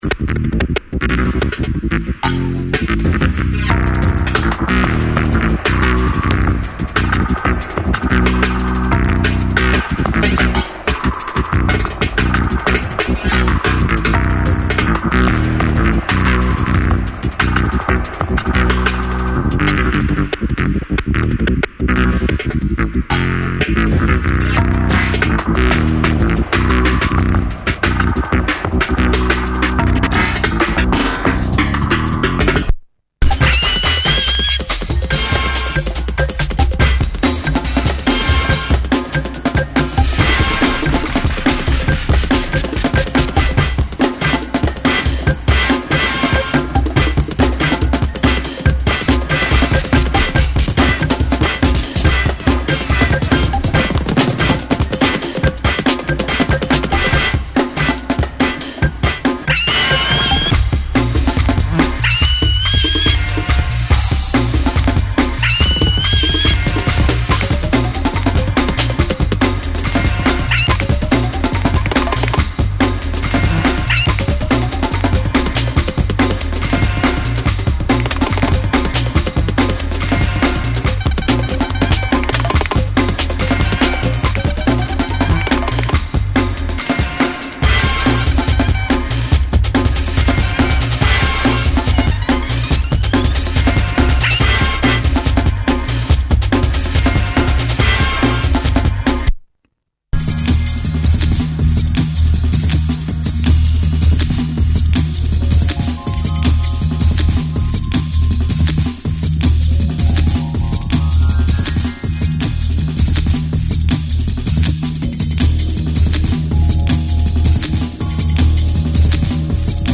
Classic D&B